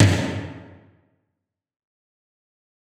Tom_D2.wav